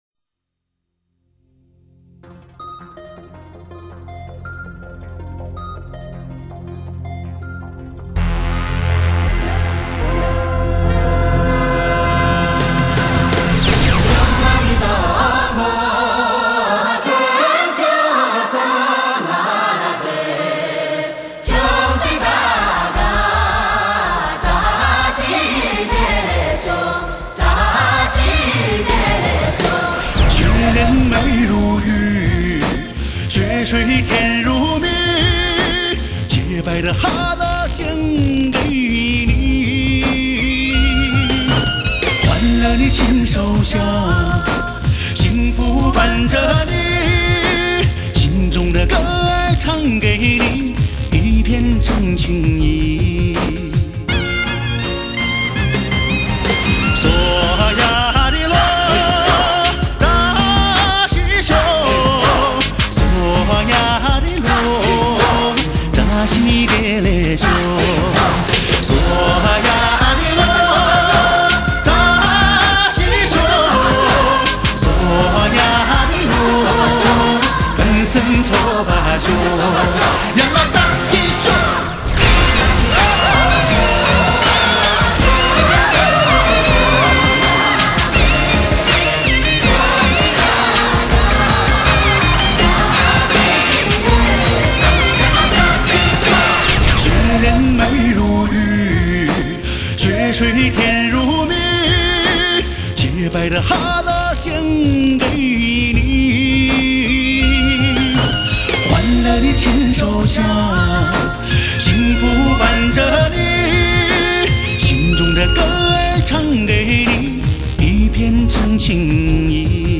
[mjh4][light]那歌声...那旋律...悠扬飘荡...[/light][/mjh4]